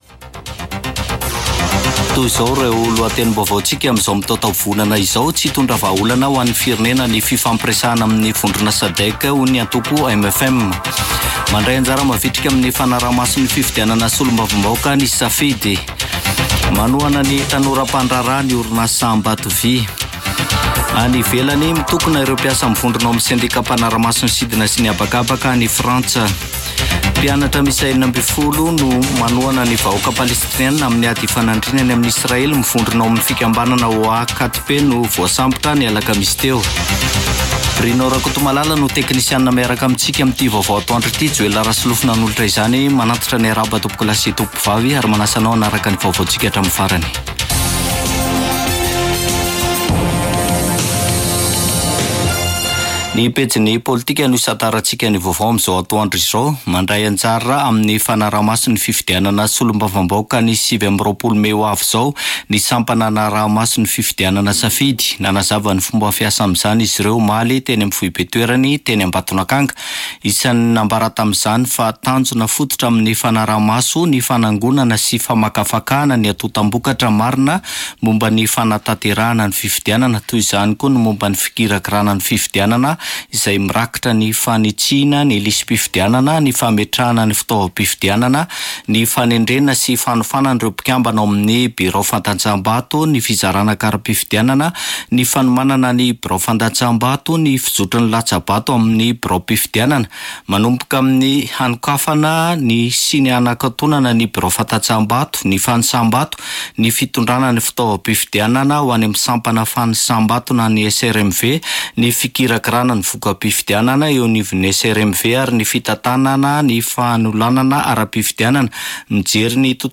[Vaovao antoandro] Sabotsy 25 mey 2024